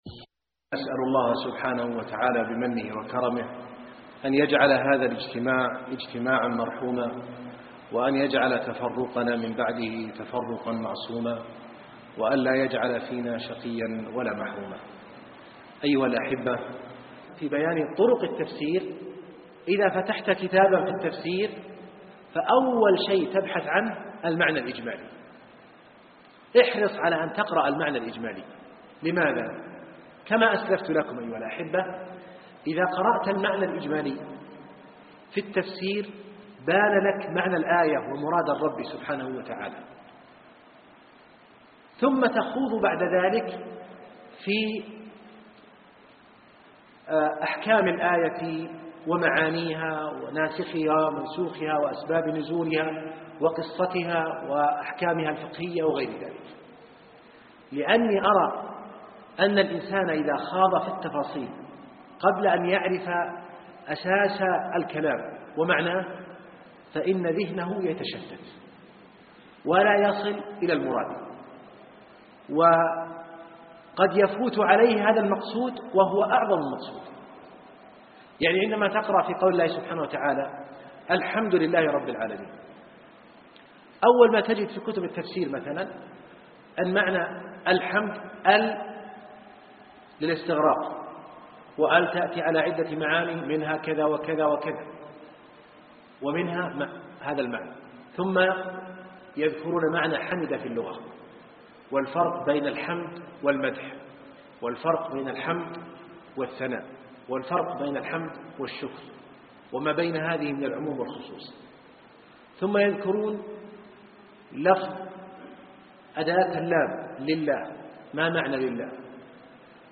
محاضرة اليوم